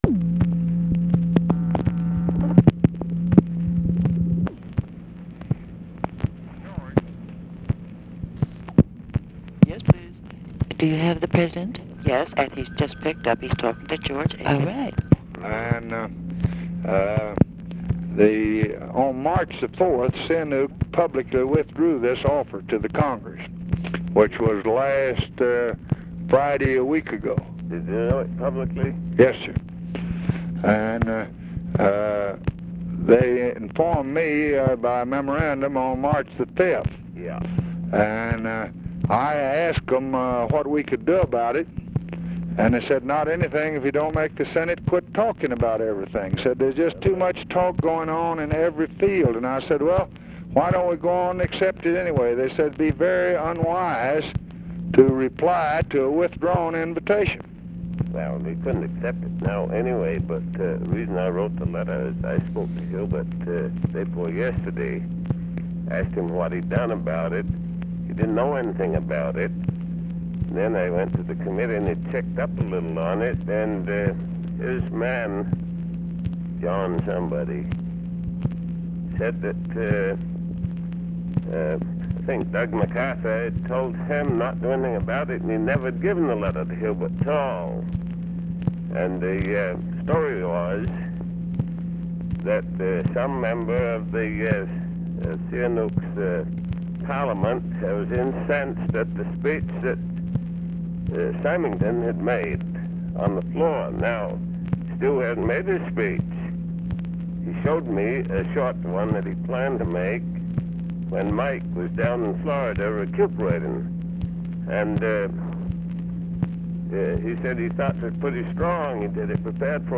Conversation with GEORGE AIKEN, OFFICE SECRETARY and TELEPHONE OPERATOR, March 11, 1966
Secret White House Tapes